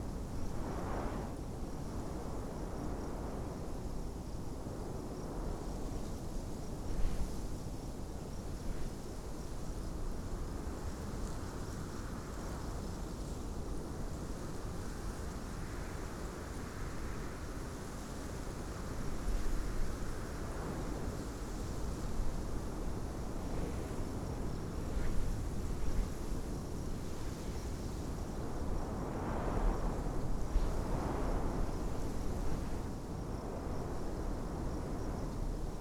night-open-loop.ogg